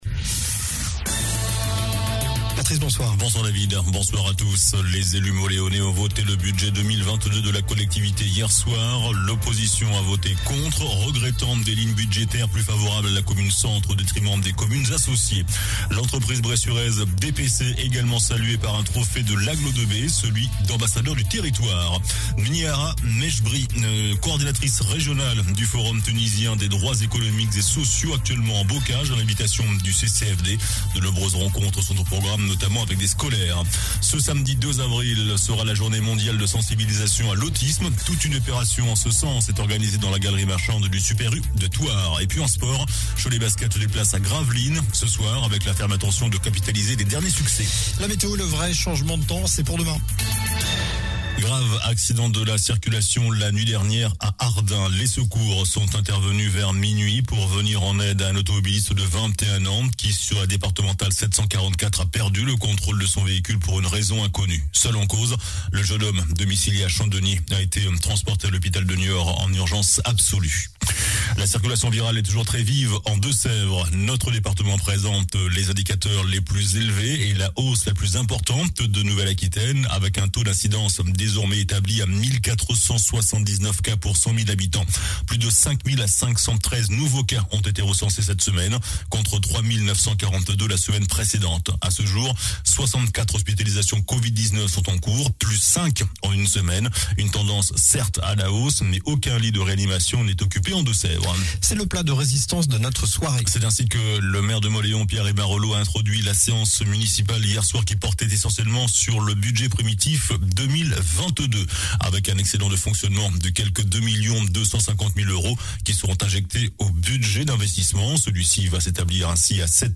JOURNAL DU MARDI 29 MARS ( SOIR )